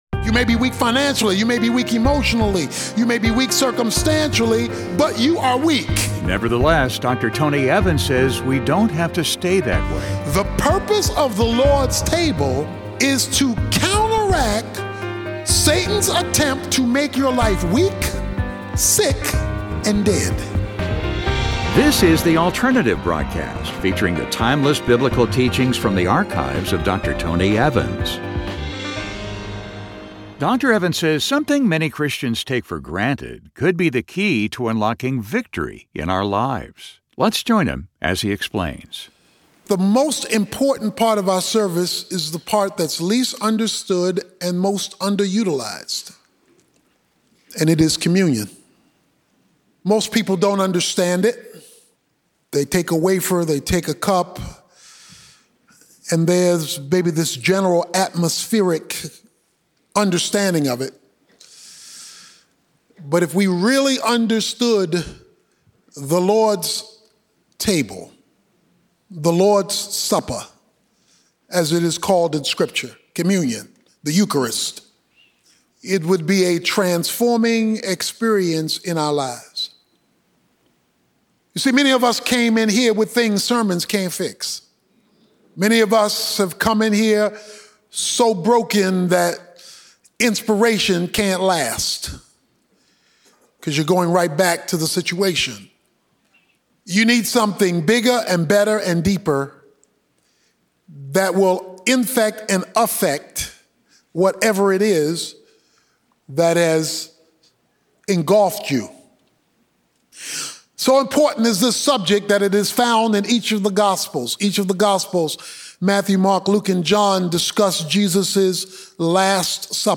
In this message, Dr. Tony Evans will point out that something many Christians consider commonplace could be the key that unlocks the door to victory in our lives. It's a look at how the celebration of communion can turn from a ritual into a revolution.